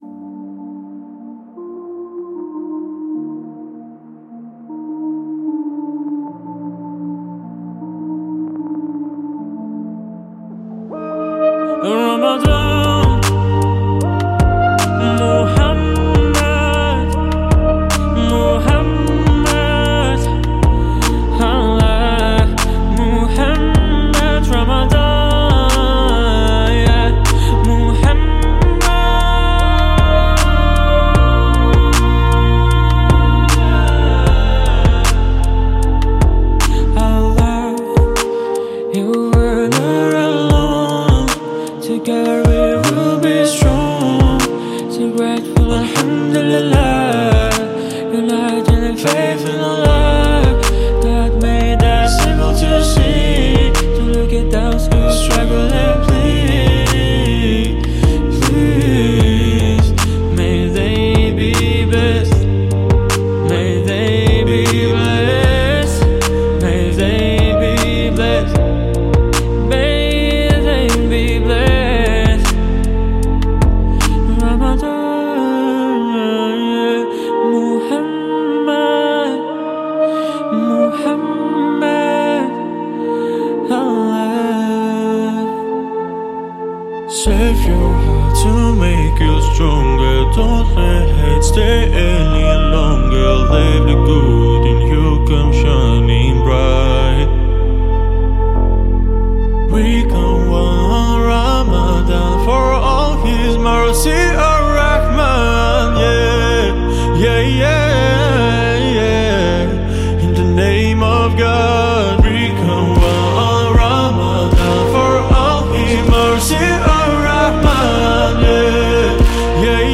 Русский поп